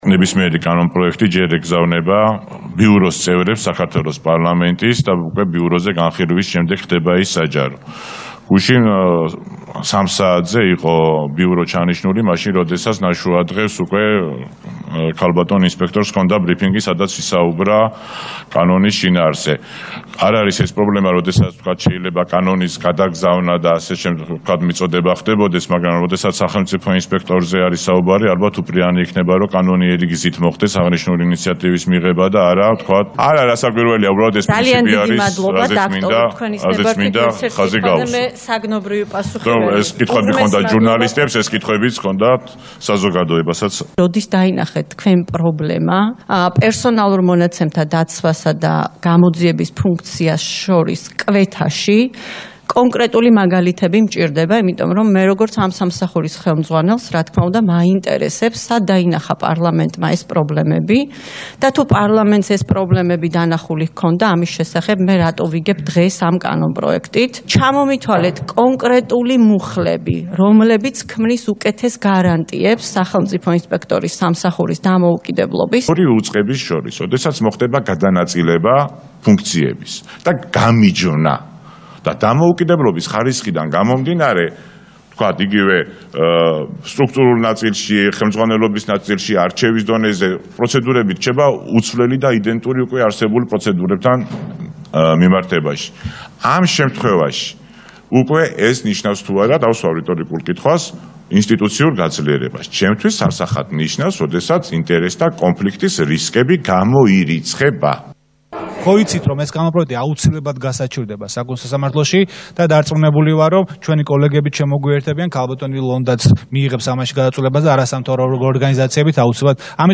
კომენტარები სხდომიდან
KOMENTAREBI-SXDOMIDAN.mp3